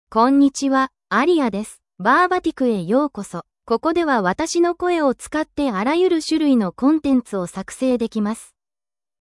AriaFemale Japanese AI voice
Aria is a female AI voice for Japanese (Japan).
Voice sample
Listen to Aria's female Japanese voice.
Female